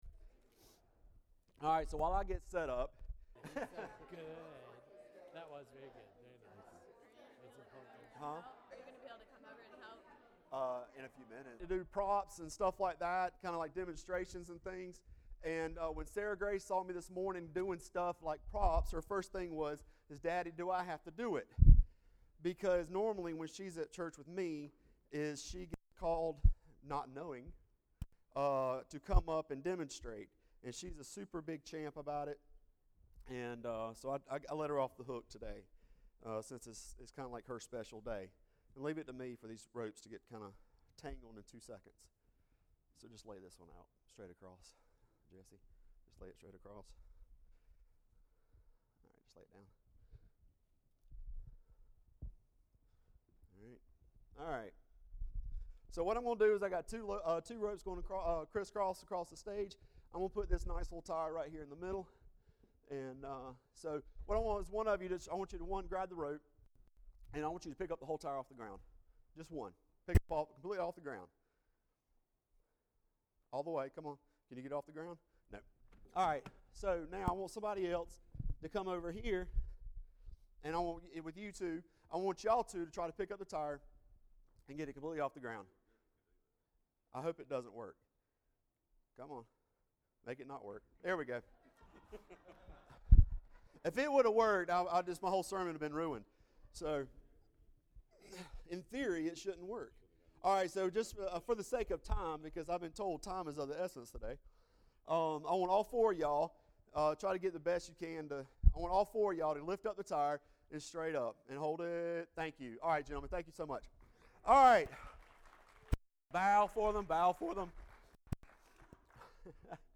Bible Text: John 17:20-26 | Preacher